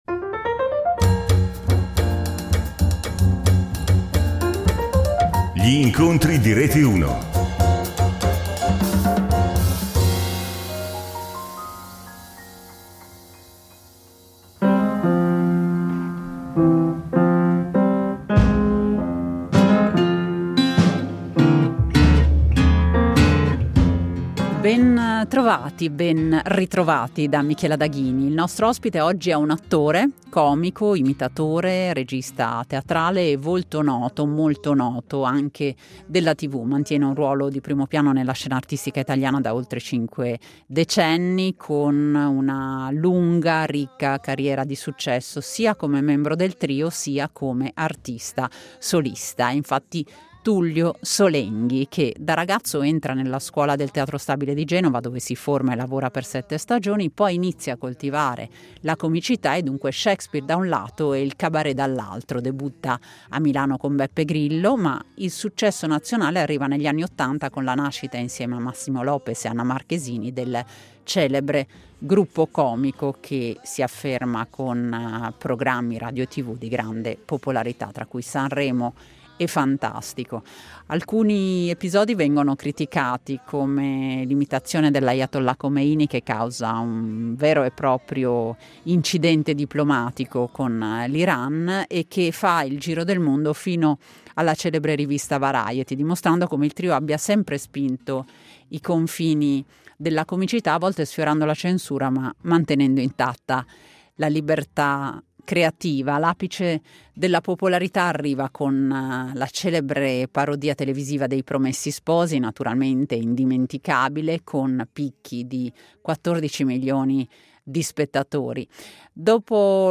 Lo abbiamo incontrato a Milano nei camerini del Teatro Carcano.